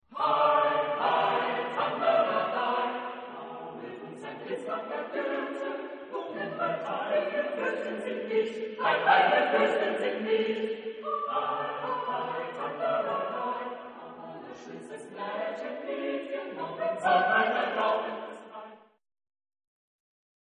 Genre-Style-Forme : Cycle ; Pièce chorale ; Profane
Type de choeur : SATB  (4 voix mixtes )
Tonalité : libre